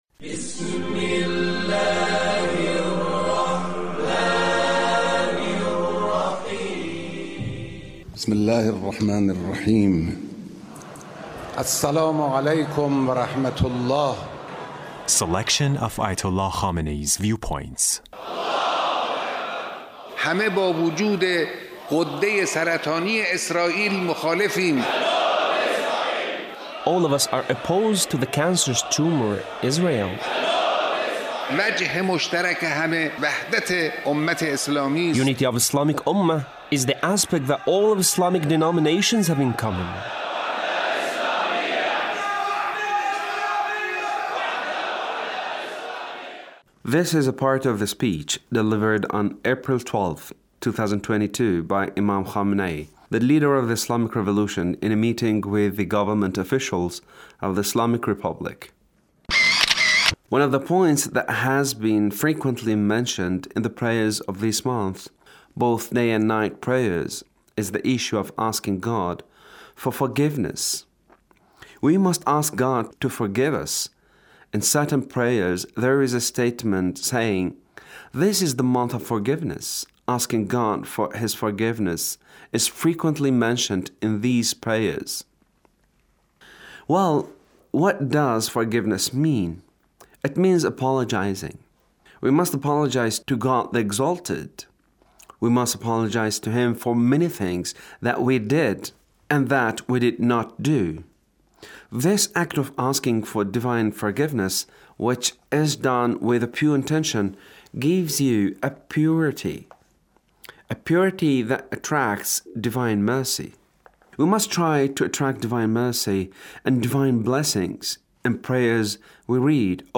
The Leader's speech on Ramadhan